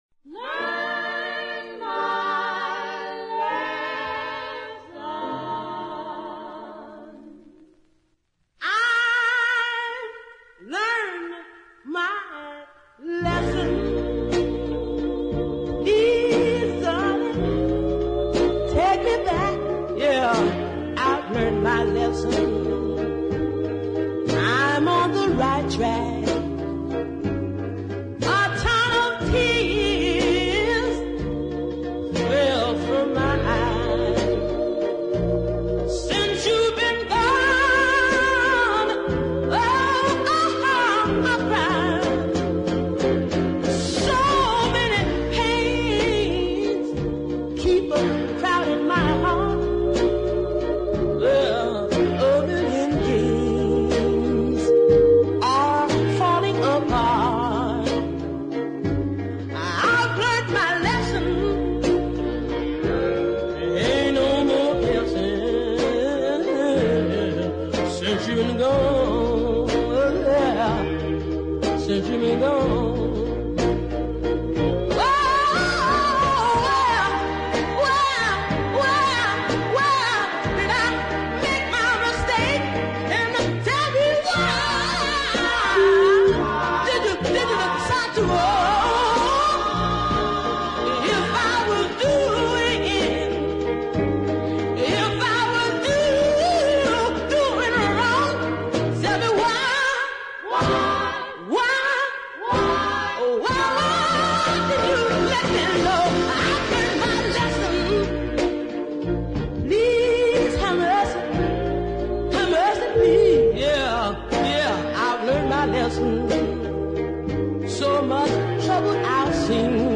first class piece of deep soul. Or is it an R & B ballad?
swooping and soaring all around the melody.